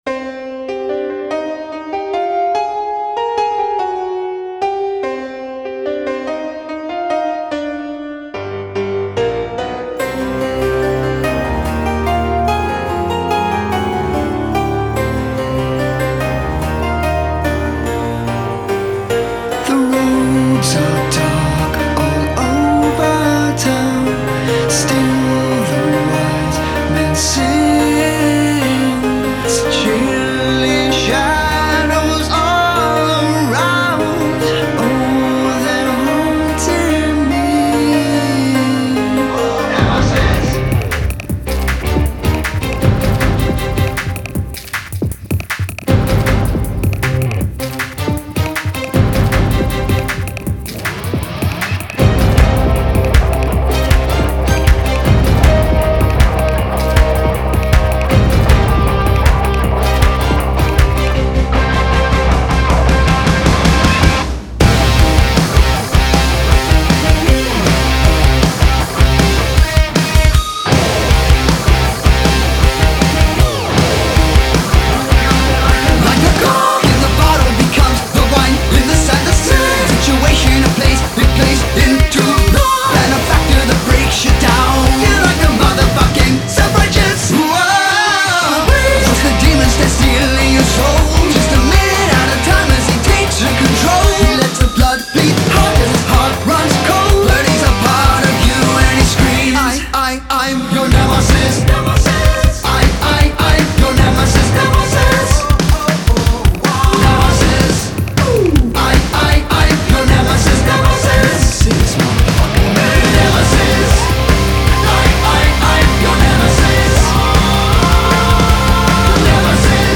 experimental hard rock